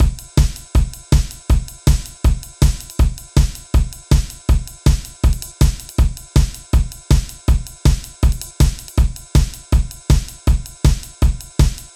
Track 16 - Drum Break.wav